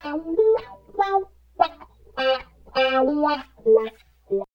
71 GTR 3  -R.wav